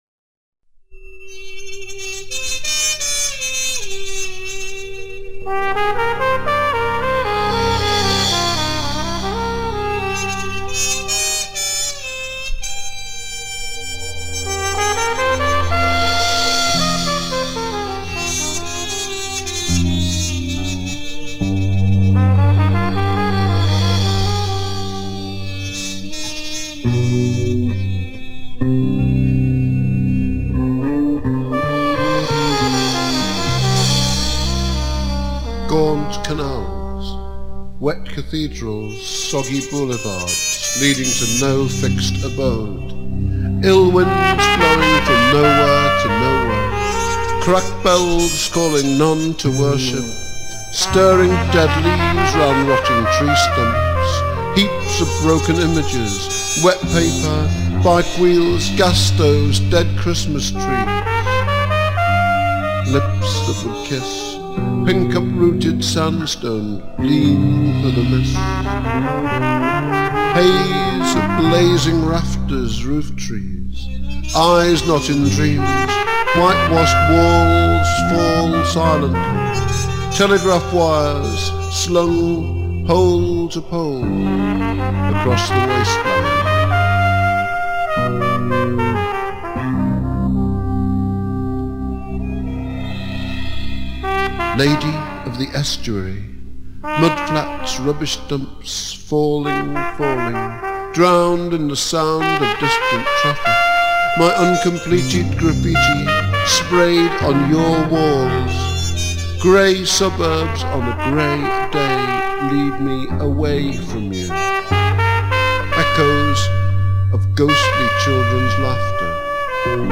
Liverpool 60's Beat Poet and the 80's Urban Wordsmith